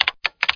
1 channel
CLAV4.mp3